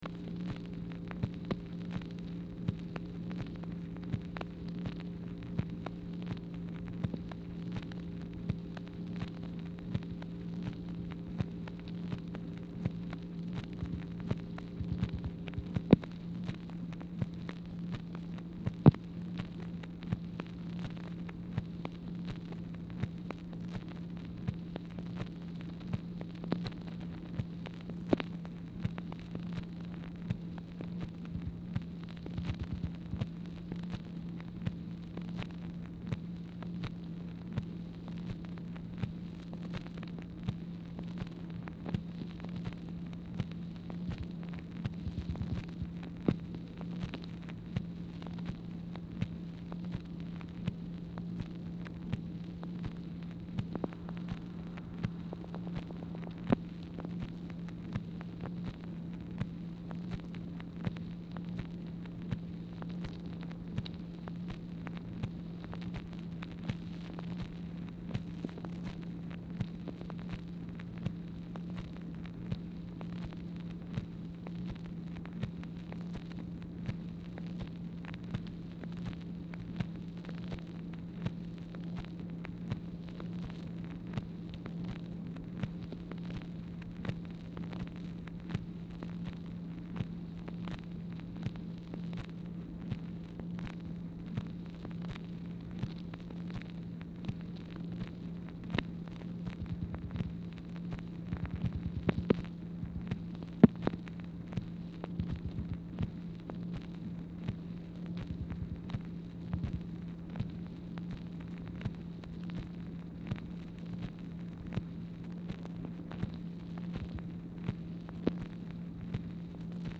Telephone conversation # 3202, sound recording, MACHINE NOISE, 4/30/1964, time unknown | Discover LBJ
Format Dictation belt
Specific Item Type Telephone conversation